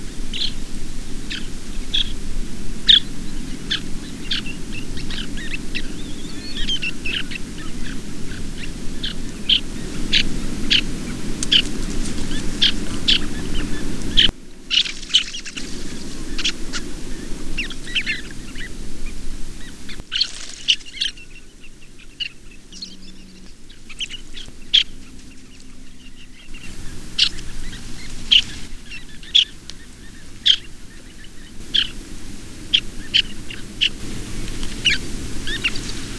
Pectoral Sandpiper
Calidris melanotos
VOICE: A hoarse "chirrup."